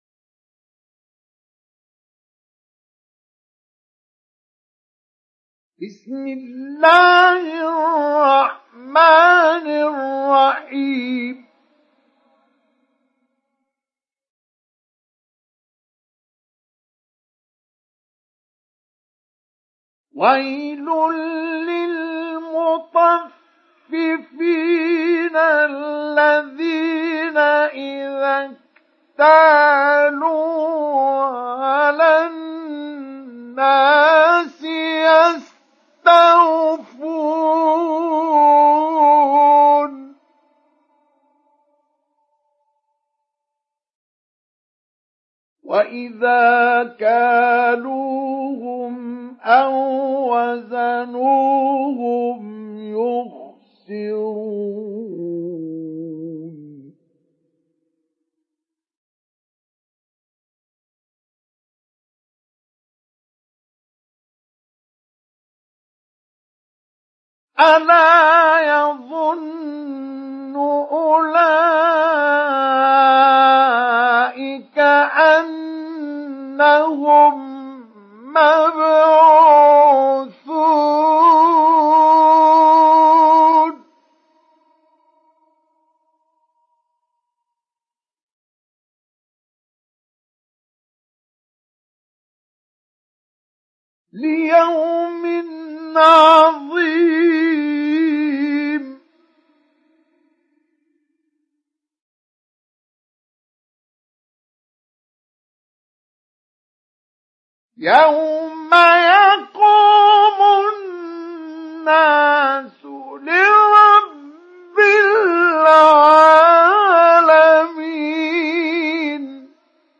Surat Al Mutaffifin mp3 Download Mustafa Ismail Mujawwad (Riwayat Hafs)